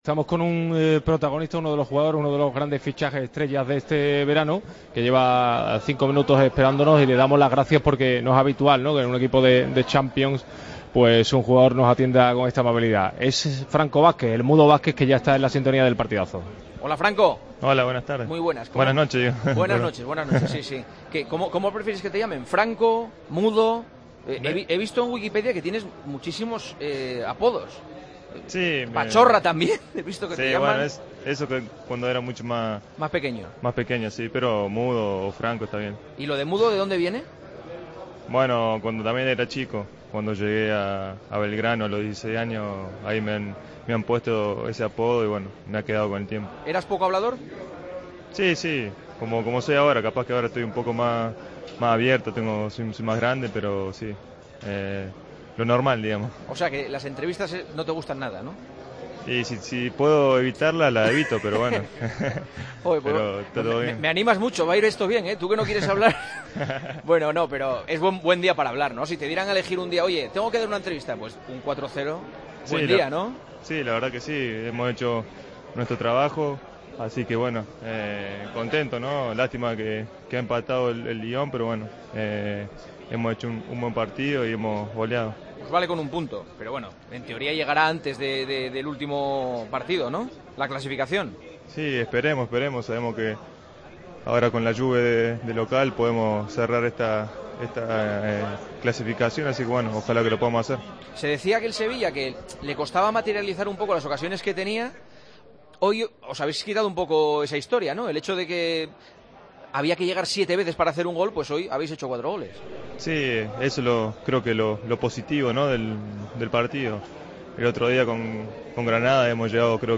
Entrevistamos al 'Mudo' Vázquez tras el 4-0 del Sevilla al Dinamo de Zagreb: "Lástima por el empate del Lyon. Esperamos cerrar la clasificación contra la 'Juve'. Pudimos hacer más goles. Muy contento con Sampaoli, es un técnico ganador".